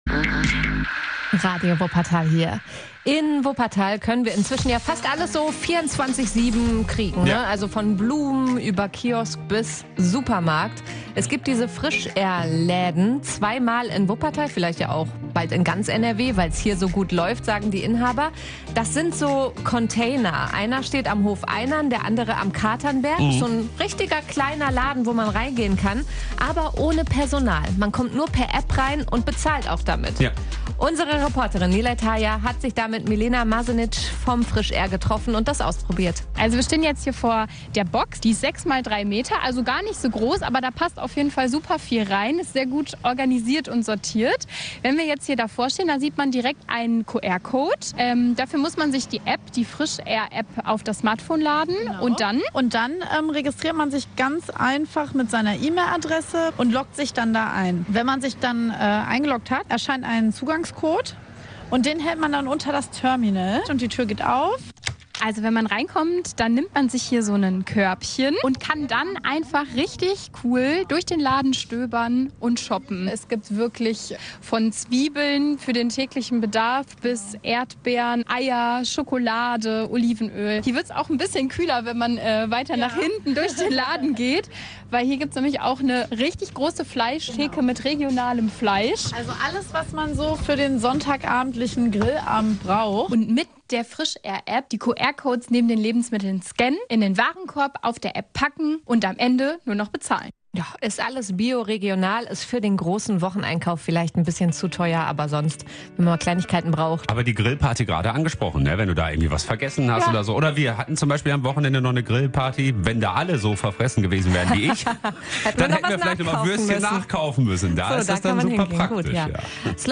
FrischR - Reportage